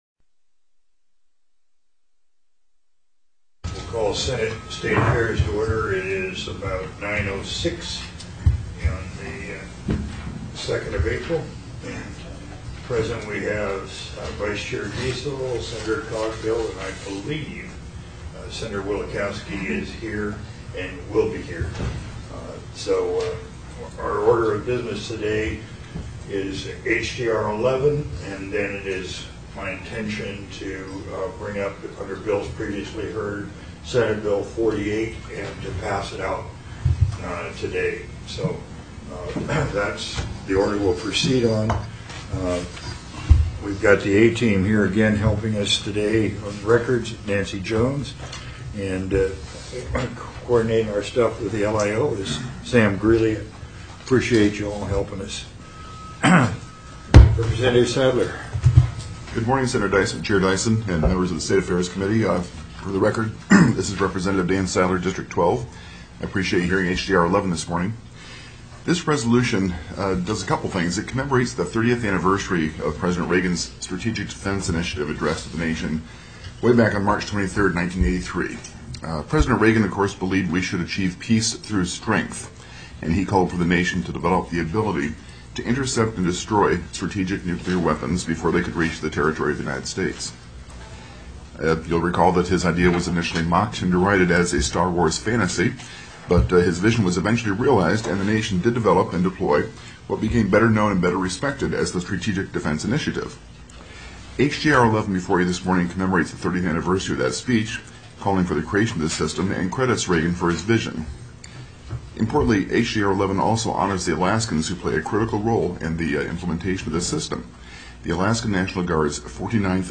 04/02/2013 09:00 AM Senate STATE AFFAIRS
TELECONFERENCED